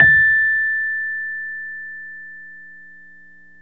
RHODES-A5.wav